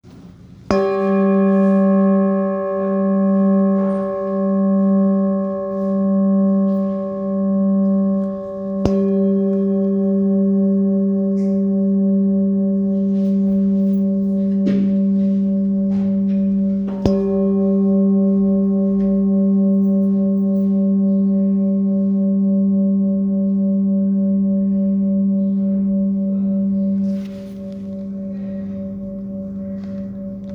Singing Bowl, Buddhist Hand Beaten, with Fine Etching Carving, Shakyamuni Buddha, Select Accessories, 20 by 20 cm,
Handmade Singing Bowls-31767
Material Seven Bronze Metal